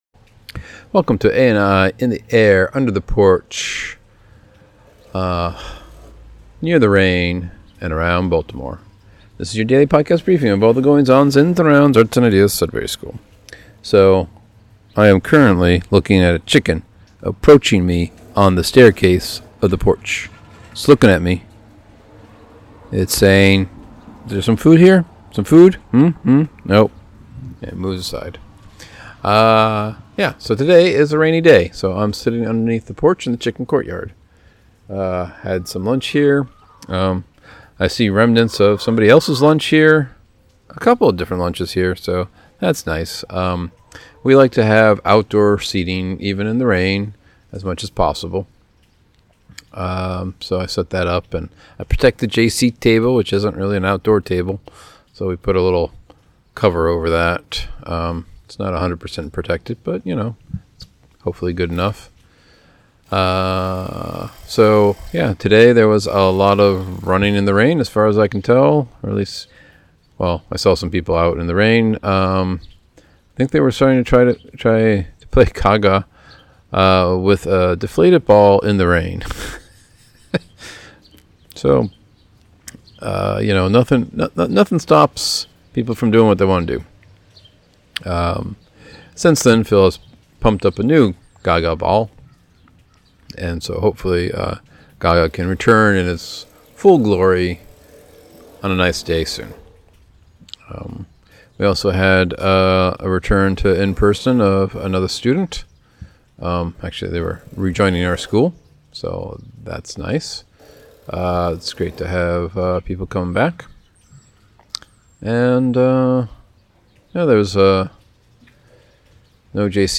Rainy day, recording from chicken courtyard porch.